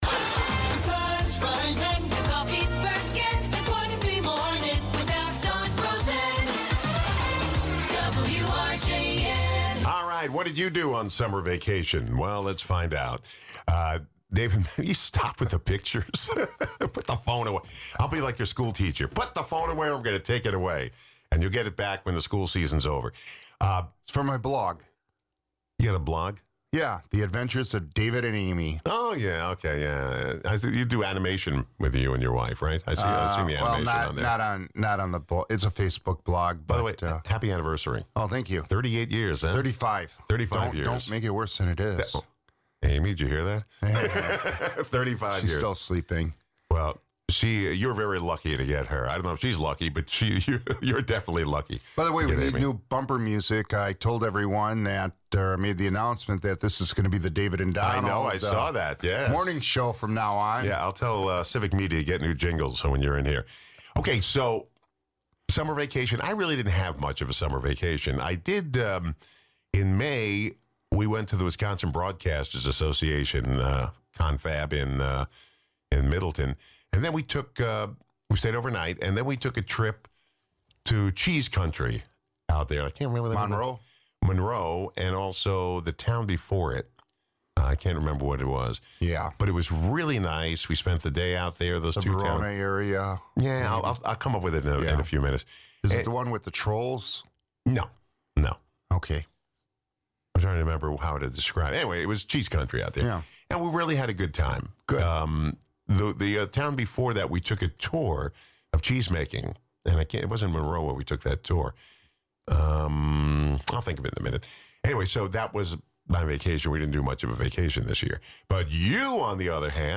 Guests: David Maack